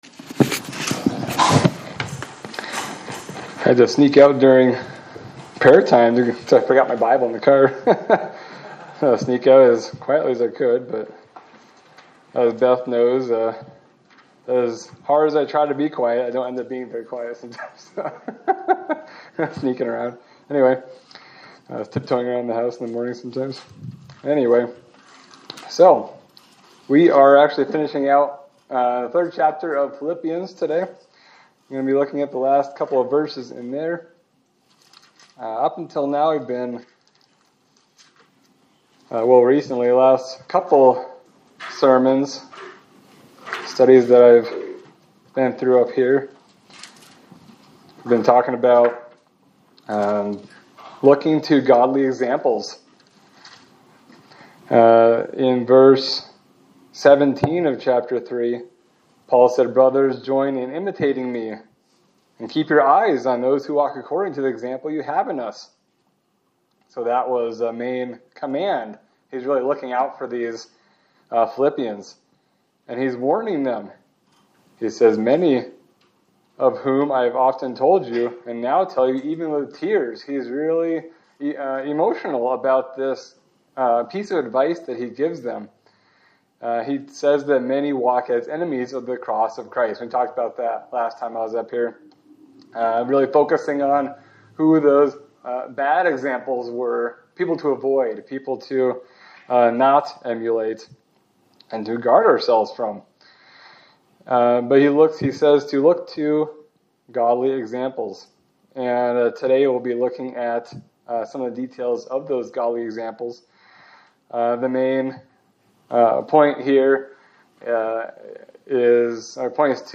Sermon for December 7, 2025
Service Type: Sunday Service